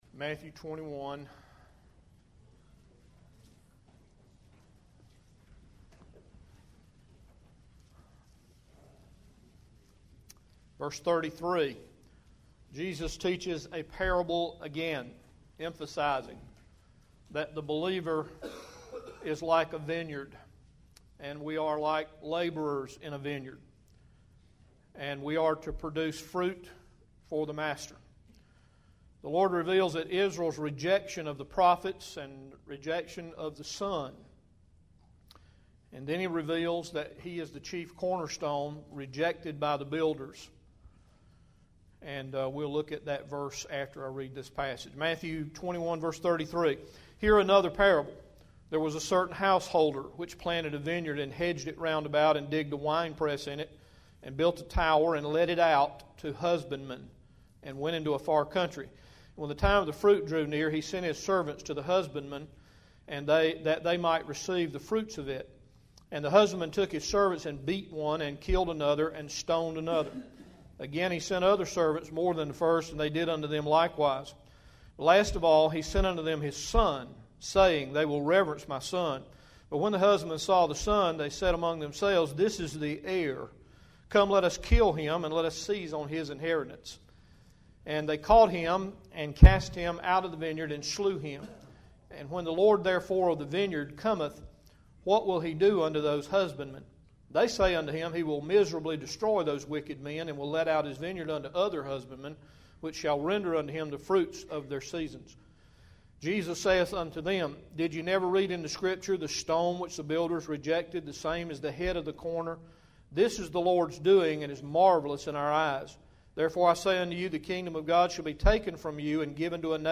April 29, 2012 AM Service Matthew Series #57 – Bible Baptist Church